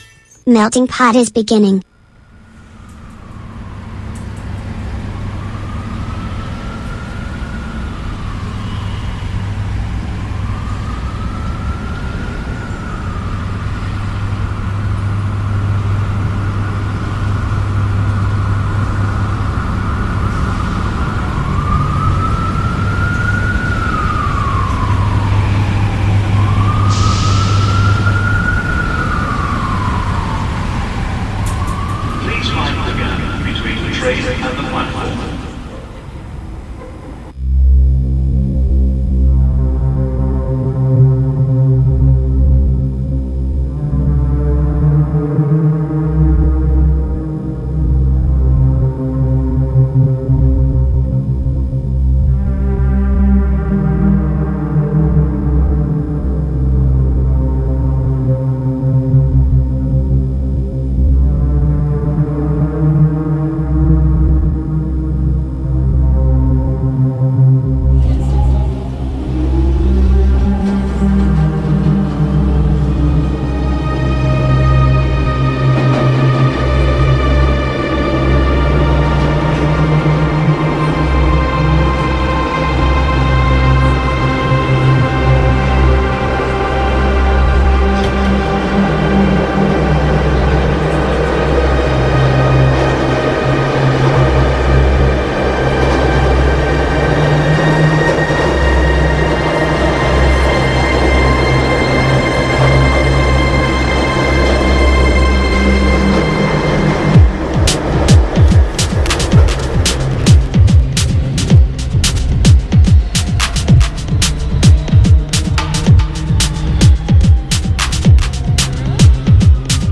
È tornato Meltingpot e la prima puntata della stagione ha confermato il suo DNA: unire musica e attualità, lasciando che le notizie più dure trovino eco e contrappunto in suoni che viaggiano liberi tra generi e generazioni.